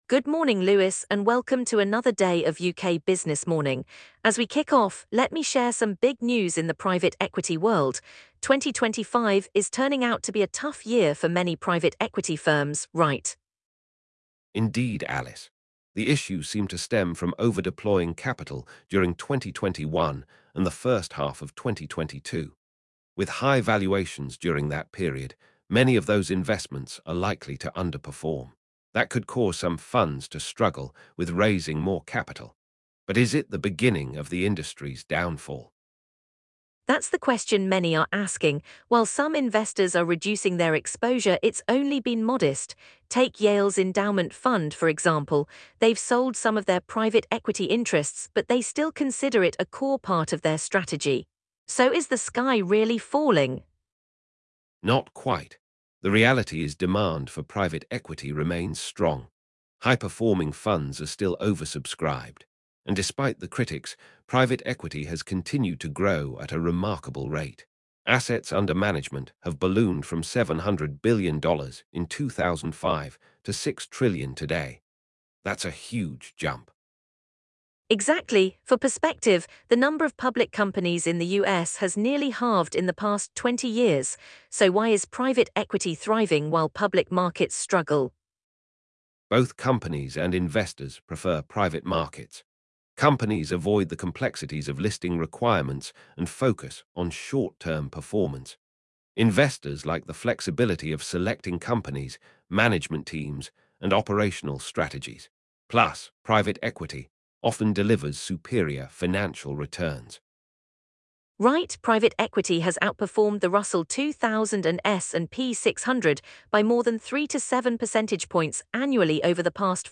The hosts explore why private equity thrives despite public market struggles, highlighting its financial returns and flexibility in strategy. They also address misconceptions about leveraged buyouts and discuss emerging initiatives that could lead to positive changes, benefiting both investors and workers.